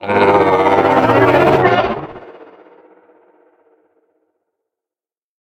Sfx_creature_pinnacarid_callout_05.ogg